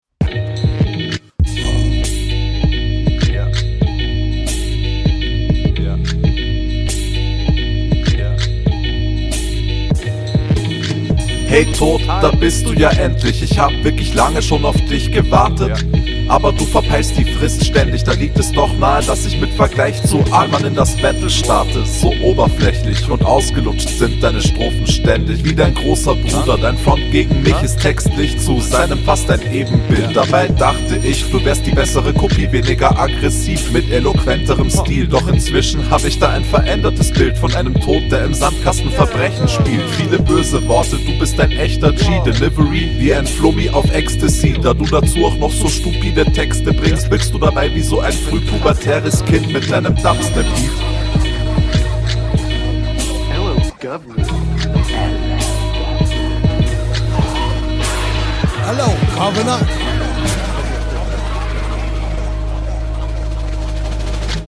Beat sehr cool. Allg klingt das wie von Nepumuk/Retrogott inspiriert aber mit eigenem flair.